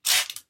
其他 " 喷雾 1
描述：将液体喷到表面上
Tag: 厨房 清洁 清洗 喷雾